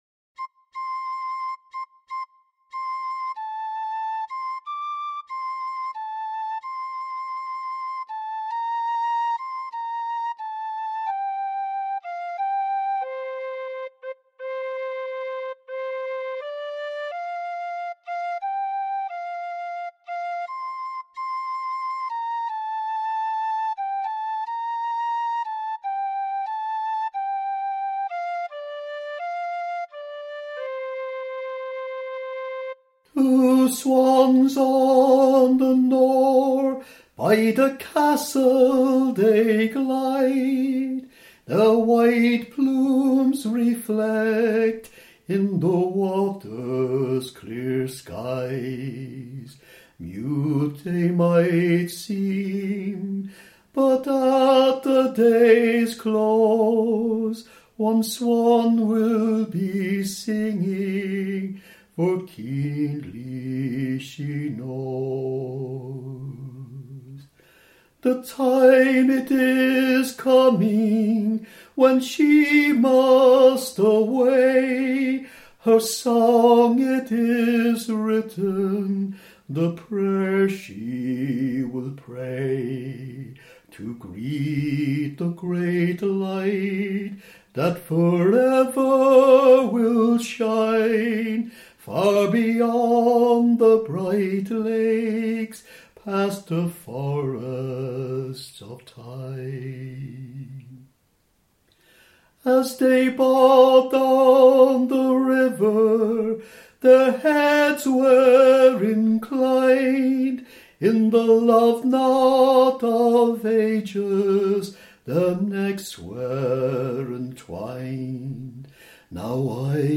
The Swans of the Nore — (F major)